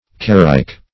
carryk - definition of carryk - synonyms, pronunciation, spelling from Free Dictionary Search Result for " carryk" : The Collaborative International Dictionary of English v.0.48: Carryk \Car"ryk\, n. A carack.